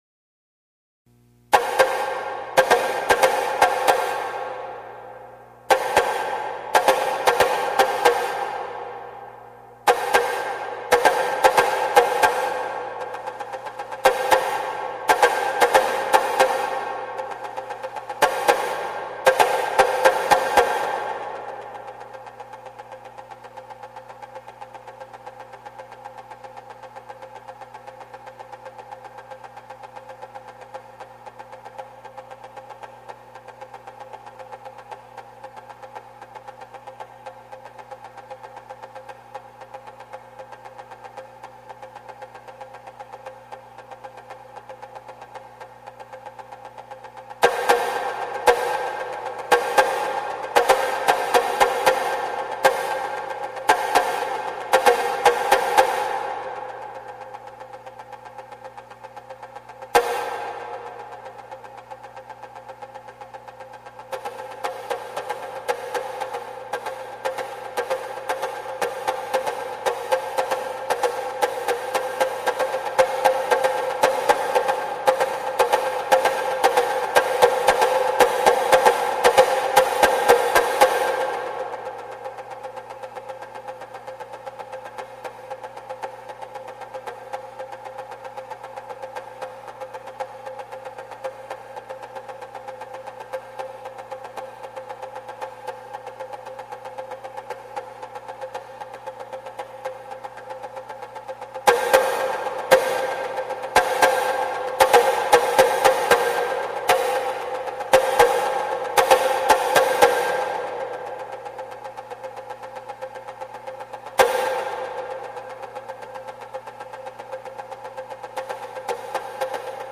使用楽器　　締太鼓 ７
締太鼓のみの曲で、どれだけ｛炎｝を表現できるかがポイントです。またこの｛炎の伝説｝には、他の曲に無い独特な緊張感があります。